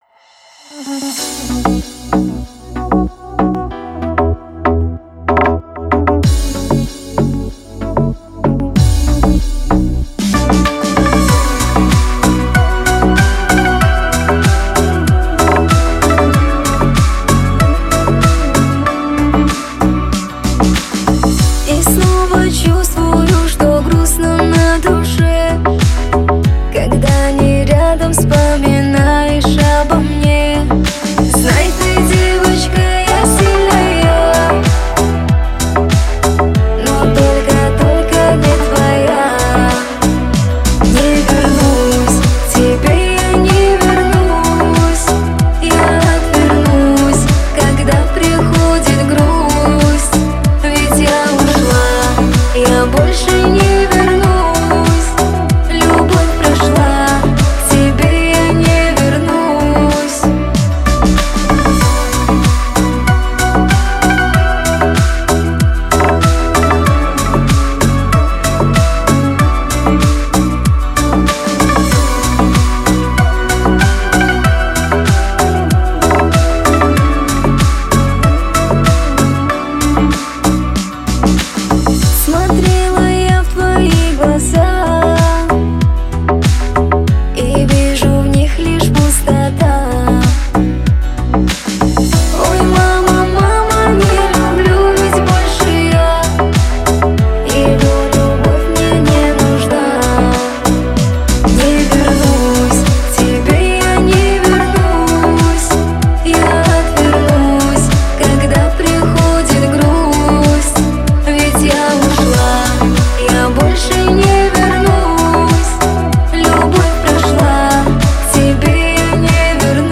Жанр: Поп/ Шансон